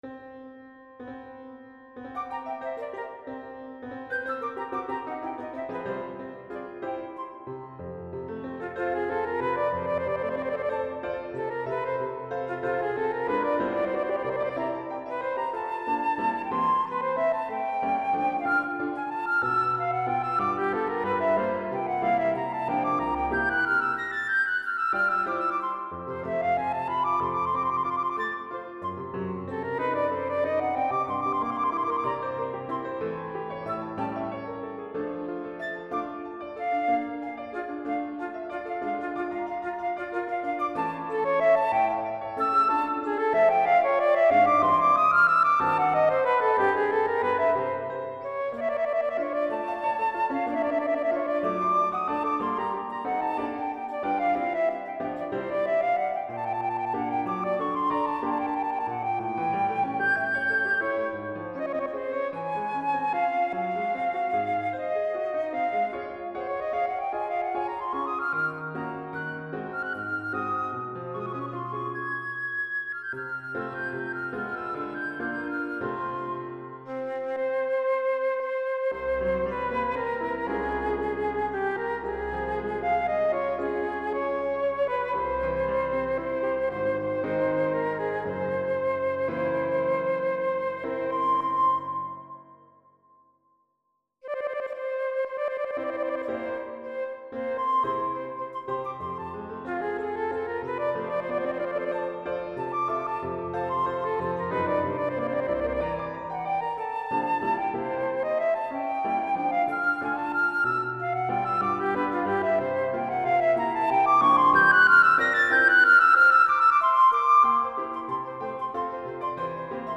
for Flute and Piano